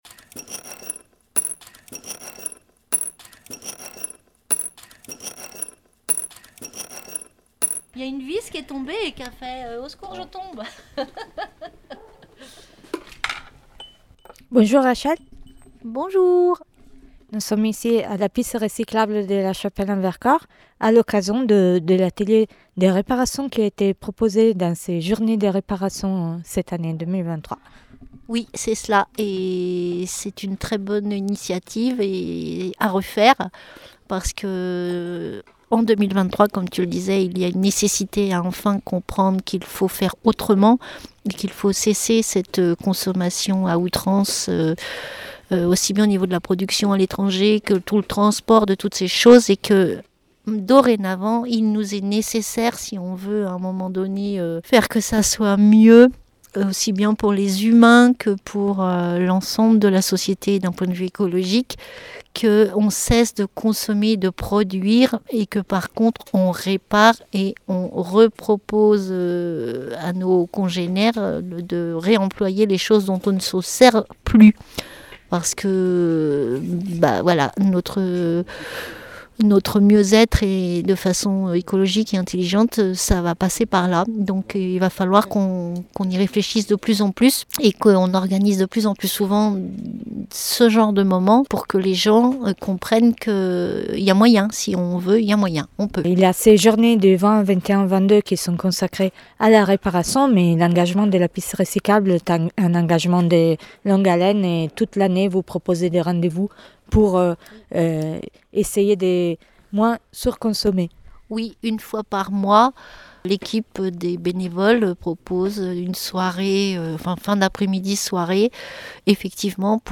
Rencontre avec les bénévoles de la piste recyclable à l’occasion de la journée nationale de la réparation le 21 octobre 2023 : nous avons parlé de l’association, de grille-pains et du sens politique de la réparation.